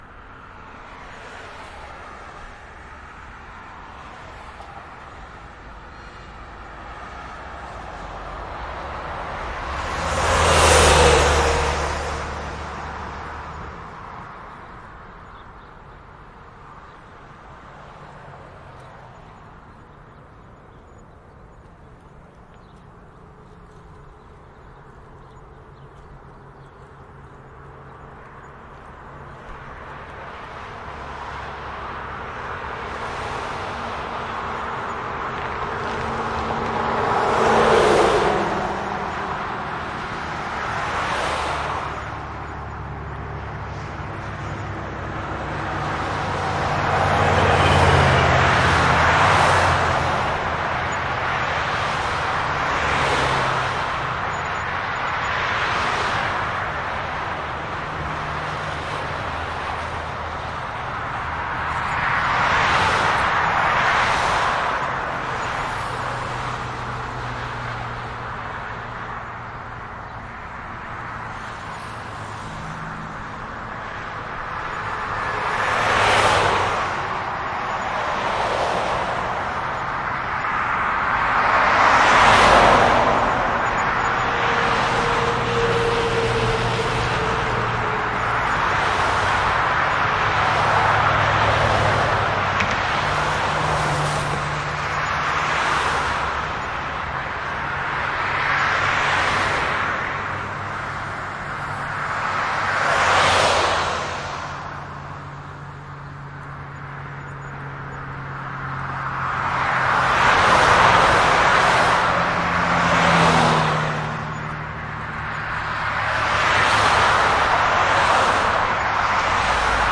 车流呼啸而过
描述：在左转弯车道等待时交通的声音... 44秒在智能手机上录制带有HiQ .mp3录音机我身后排队的跑道有他的动臂箱，但它在BG中很微弱。
标签： 高速公路 传递 4 交通 汽车 卡车 泳道
声道立体声